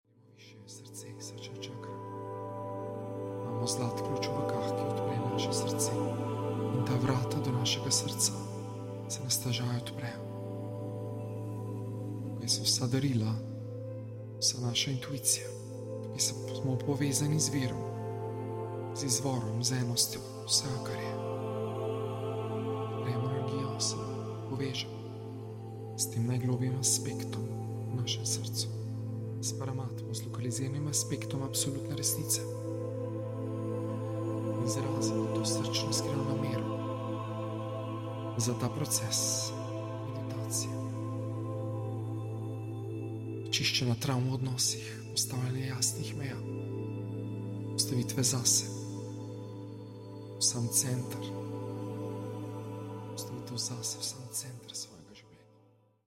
Posnetek meditacije je bil izrezan ter zvočno obdelan iz 22. srečanje članstva Osvobajanje od iluzij, 30.05.2024 (Meditacija Zdravljenje toksičnih odnosov).